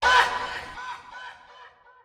Vox (Mama).wav